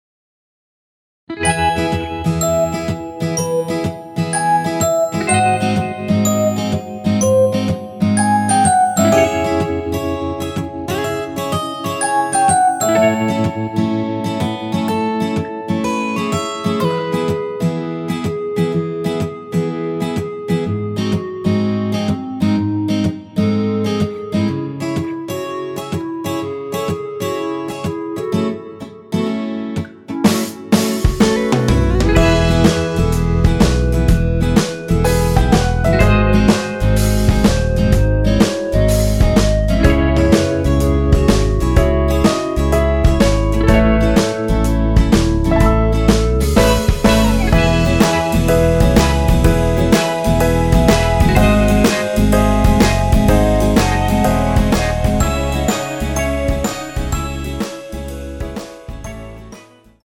원키에서(-8)내린 멜로디 포함된 MR입니다.
앞부분30초, 뒷부분30초씩 편집해서 올려 드리고 있습니다.
중간에 음이 끈어지고 다시 나오는 이유는